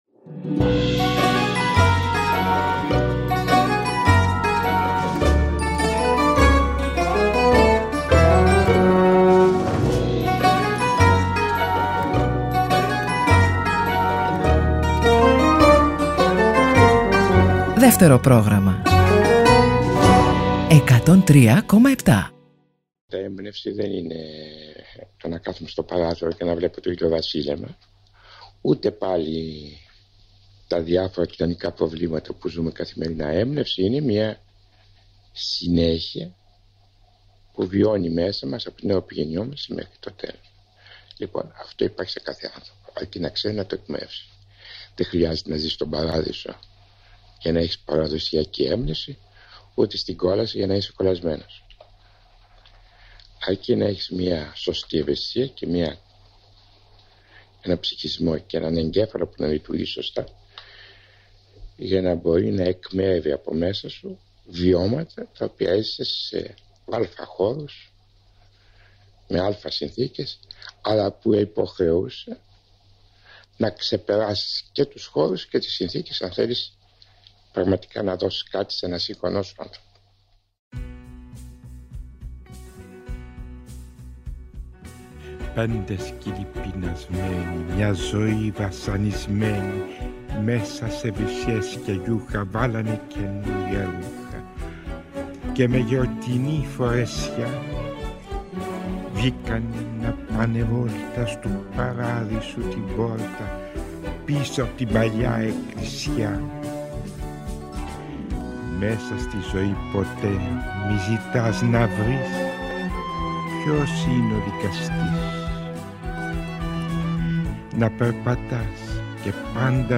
Θα ακουστούν αποσπάσματα από γνωστές και λιγότερο γνωστές τηλεοπτικές, ραδιοφωνικές και έντυπες συνεντεύξεις του.